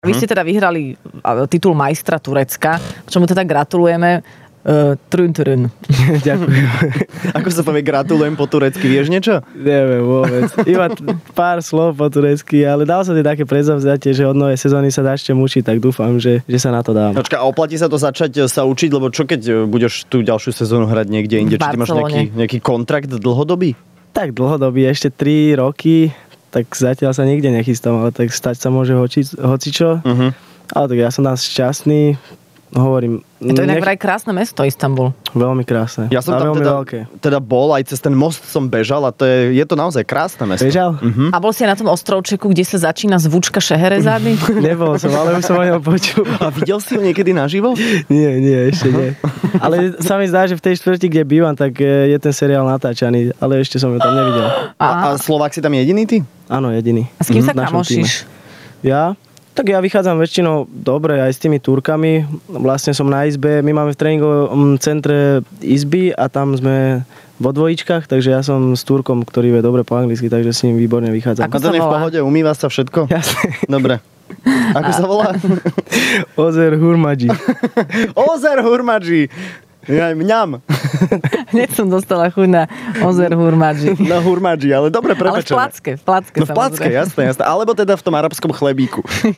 V Rannej šou Fun rádia bol hosťom futbalista Miňo Stoch.